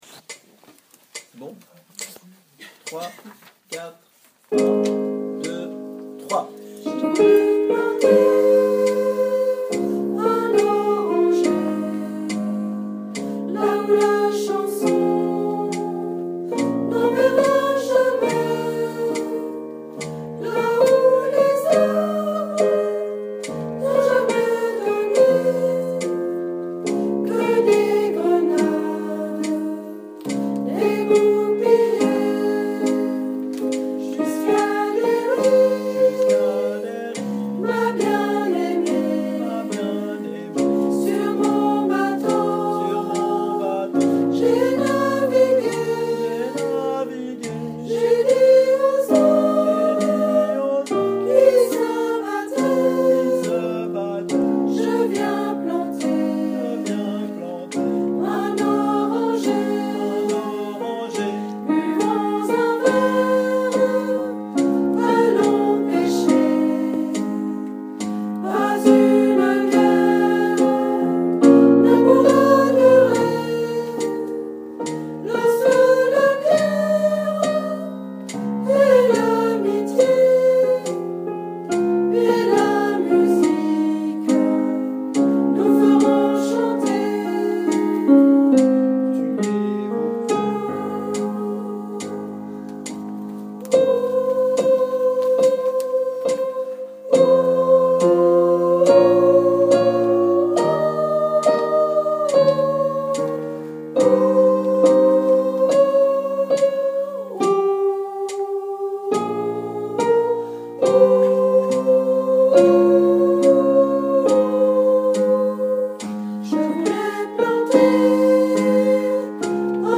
Soprani 1
Ballade-sop1.mp3